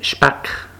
Stimmlose bilabiale Plosiv
• De Artikulationsort isch bilabial; er wird mit de Ùnter- ùn Oberlippe zämme artikuliert.
Fortis Späck Gsw-solothurnisch-wasseramt-Späck.ogg
Gsw-solothurnisch-wasseramt-Späck.ogg